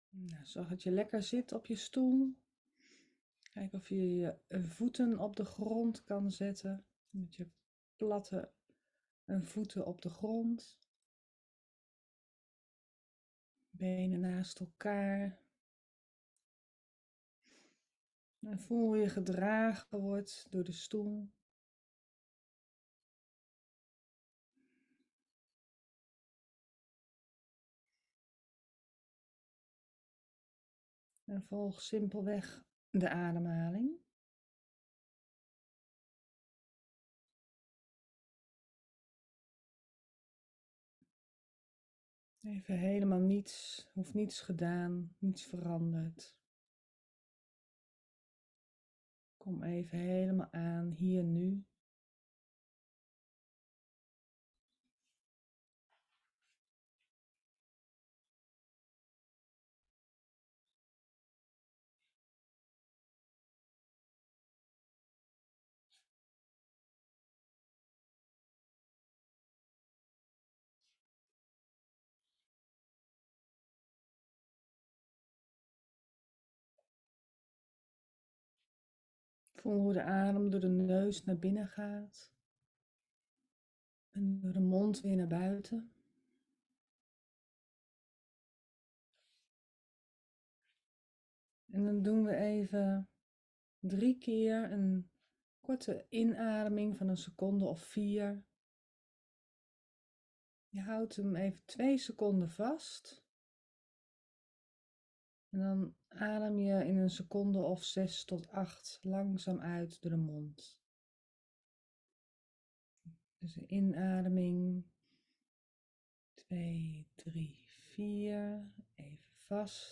Meditatie-aarding-vanuit-bekken-en-hele-lichaam-meenemen-les-4